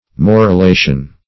Search Result for " morulation" : The Collaborative International Dictionary of English v.0.48: Morulation \Mor`u*la"tion\, n. (Biol.)